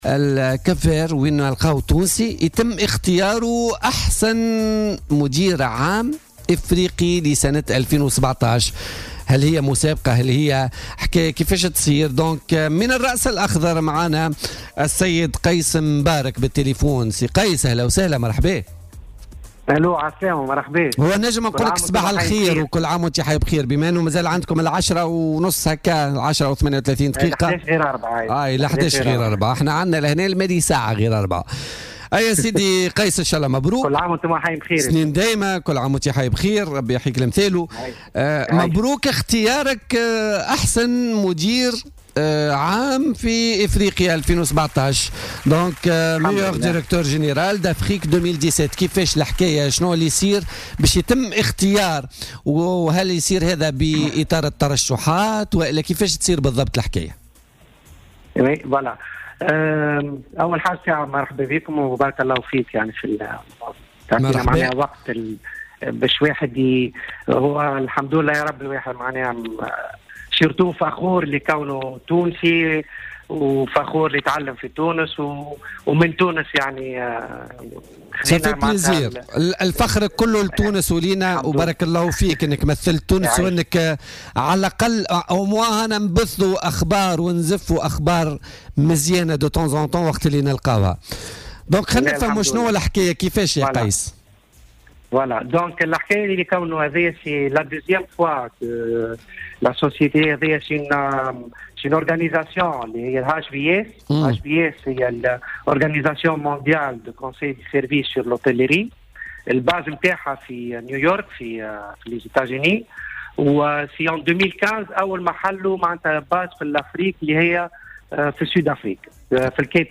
وكشف في اتصال هاتفي بـ "بوليتيكا" من دولة الرأس الأخضر، أنه بدأ مسيرته بالعمل طبّاخا ليصبح مديرا عاما في فندق حاليا ويفوز بلقب الأفضل افريقيا.